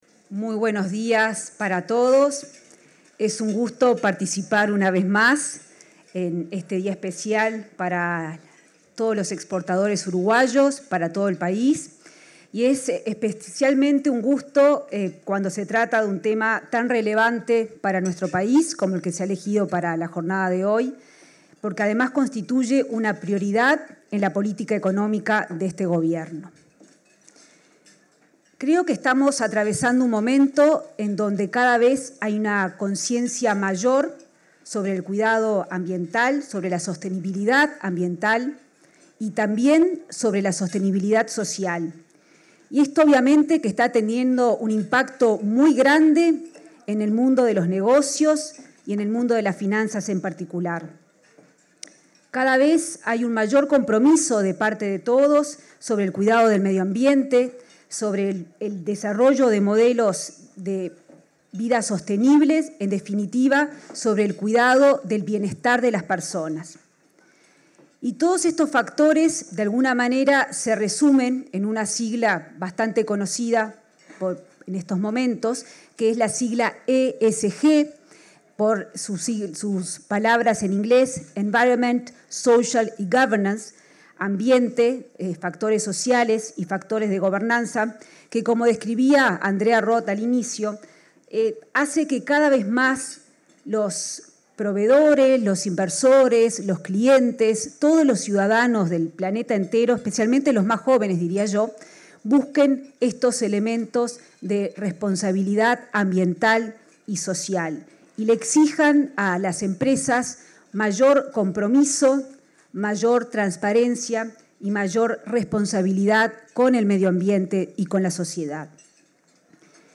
Palabras de la ministra de Economía y Finanzas, Azucena Arbeleche
El Banco República y la Unión de Exportadores del Uruguay realizaron, este 26 de julio, un almuerzo empresarial para celebrar el 50.° de esa
La ministra Arbeleche participó del evento.